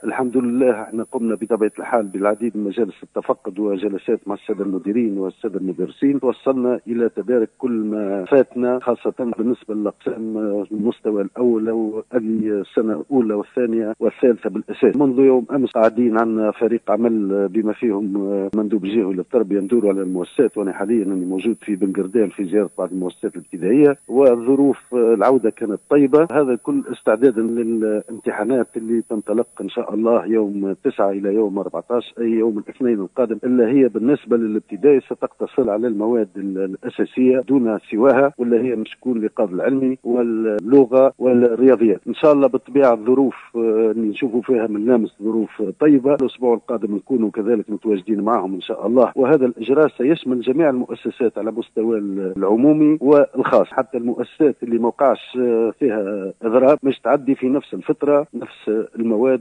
وقال المندوب الجهوي للتربية بمدنين محرز نجيب بن حميدة لمراسلتنا بالجهة إن كل الظروف ملائمة بكل المؤسسات التي وقع زيارتها إلى اليوم، بعد جلسات مع المديرين والإطارات التربوية ،مؤكدا أن الامتحانات ستكون لأقسام سنوات الأولى والثانية والثالثة بكل المدارس الابتدائية دون استثناء على أن تجرى الامتحانات فقط للمواد الأساسية وهي الإيقاظ العلمي والرياضيات واللغة من 9 جانفي إلى 14 من الشهر نفسه (تسجيل)